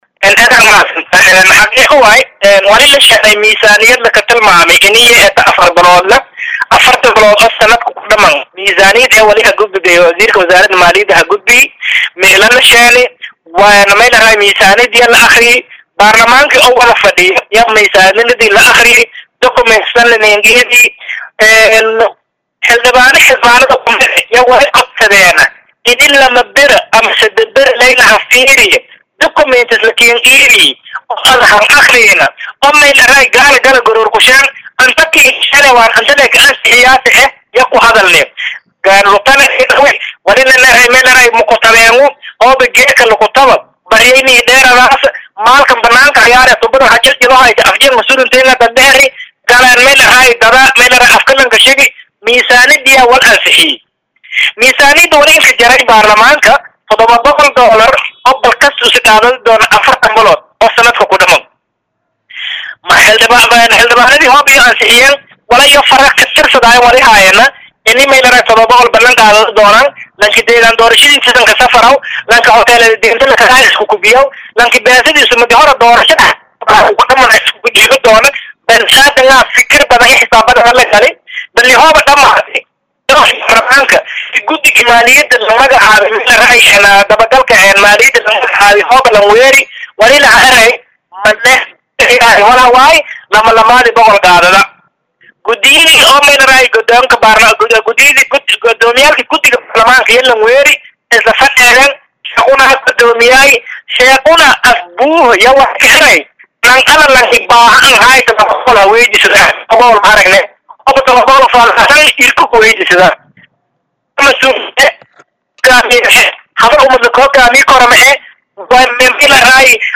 Halkan Ka Dhageyso Wareysiga Xildhibaan Xasan Black